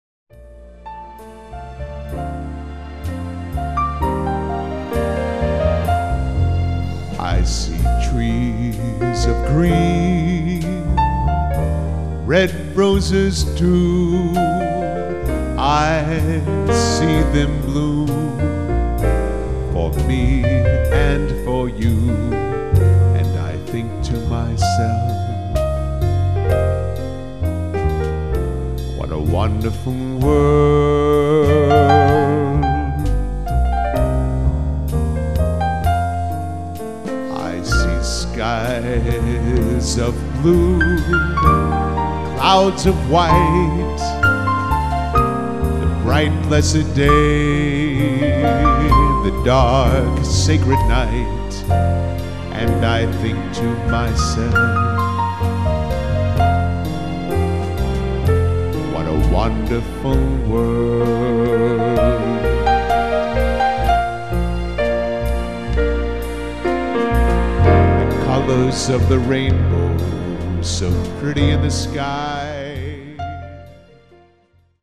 band/vocal